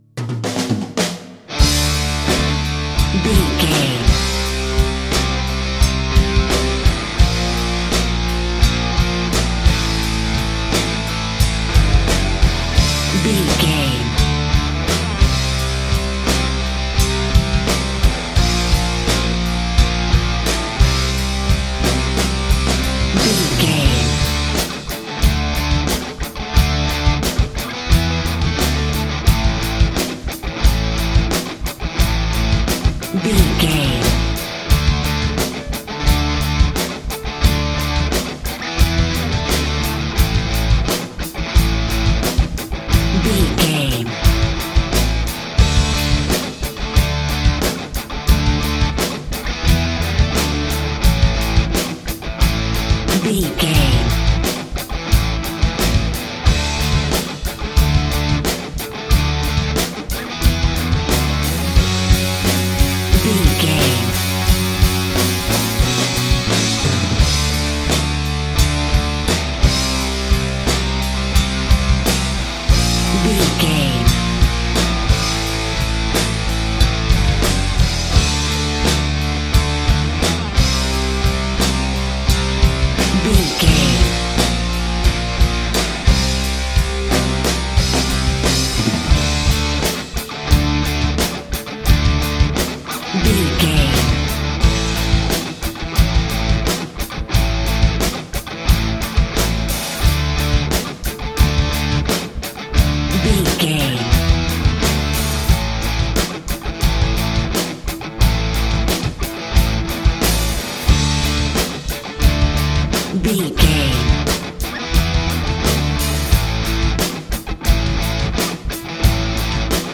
Aeolian/Minor
Slow
drums
electric guitar
bass guitar
hard rock
aggressive
energetic
intense
nu metal
alternative metal